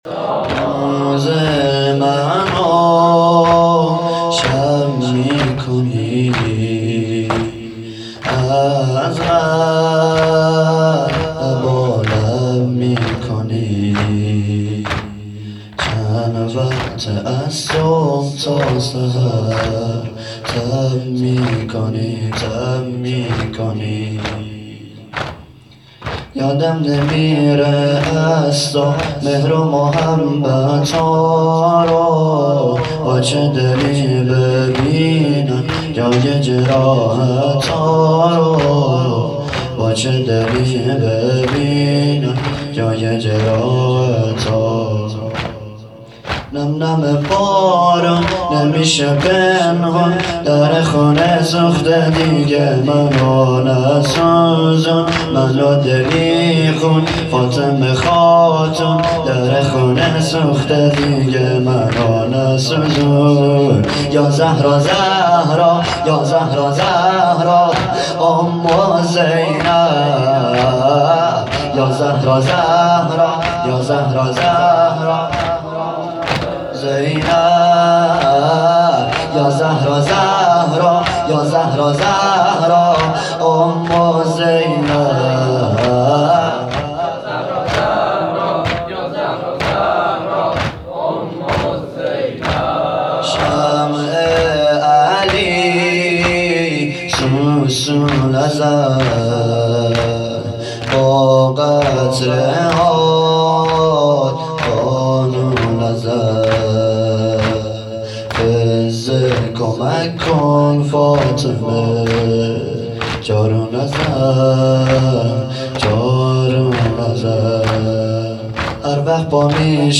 توضیحات: هیئت صادقیون حوزه علمیه زابل
زمینه-جدید.mp3